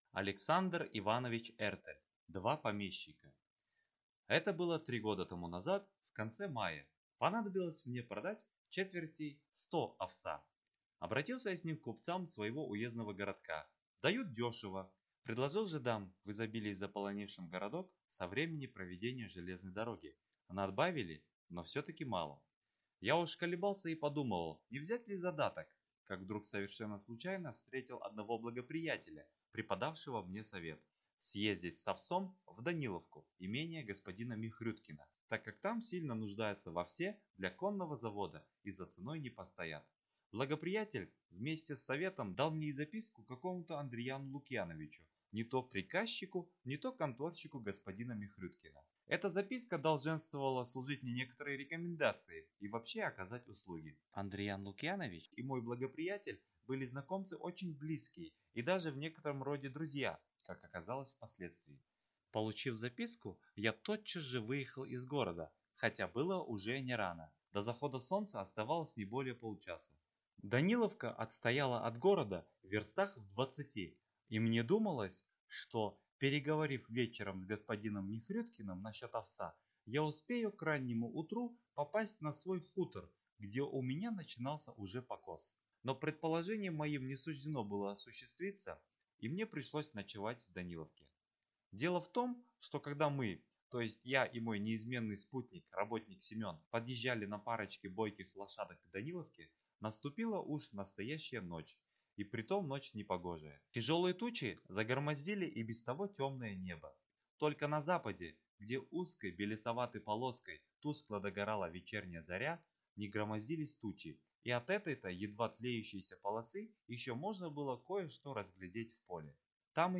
Аудиокнига Два помещика | Библиотека аудиокниг